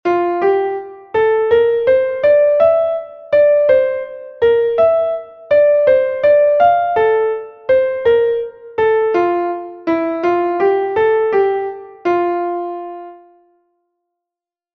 You will use 3/8 time signature, two quavers and a quaver rest.